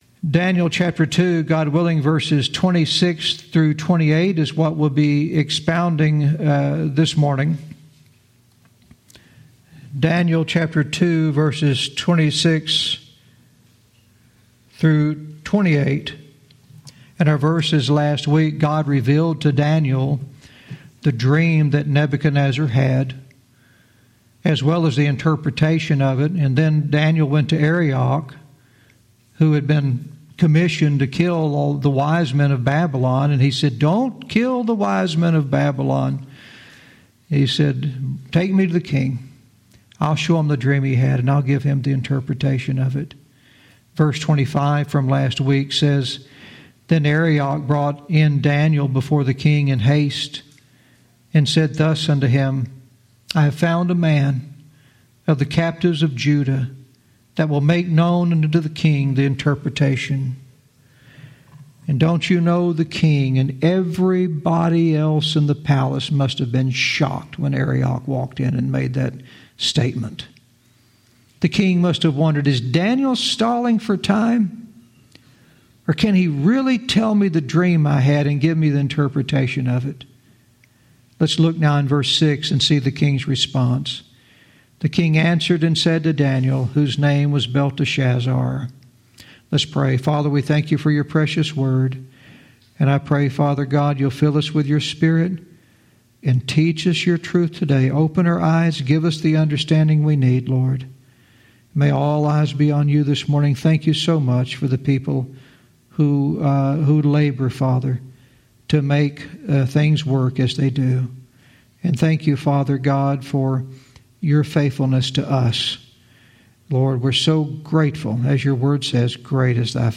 Verse by verse teaching - Daniel 2:26-28